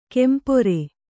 ♪ kempore